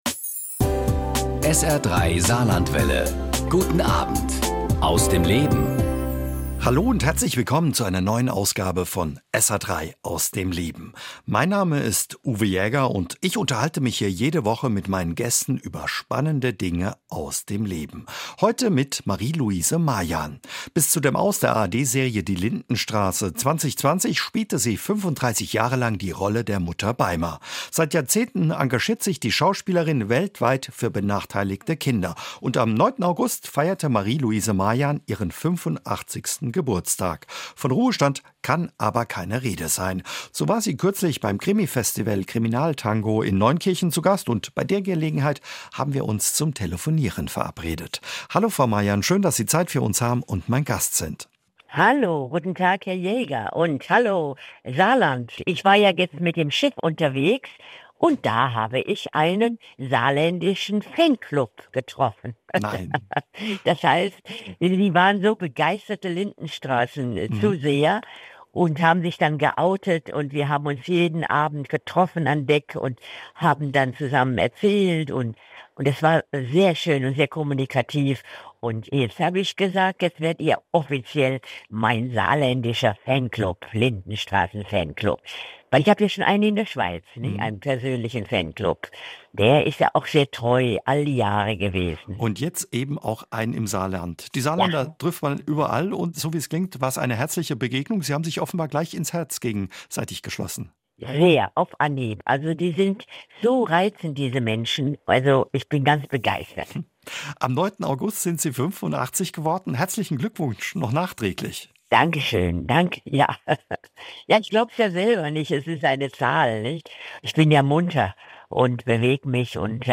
Ein Gespräch über die Rolle ihres Lebens, das Älterwerden und ihr soziales Engagement.